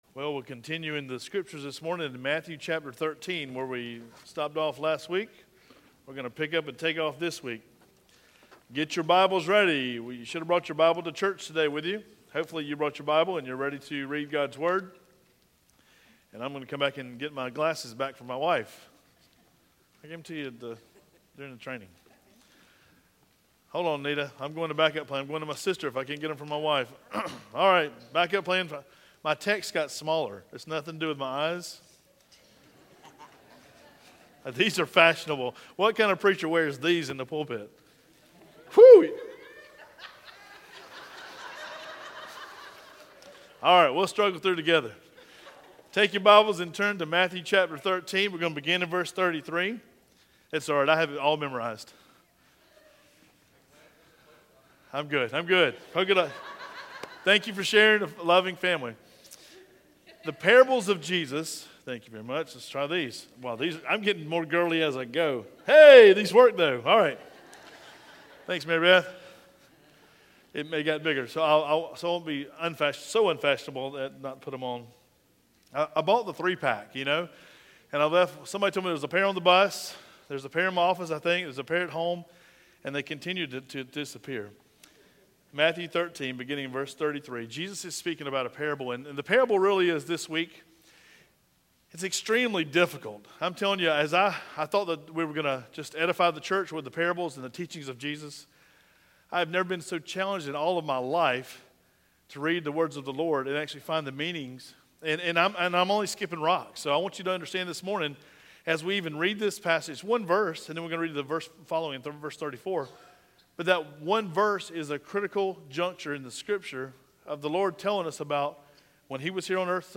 Sermon Direct Link Matthew 13 | Matthew 16 | 1 Corinthians 5 | 1 Peter 1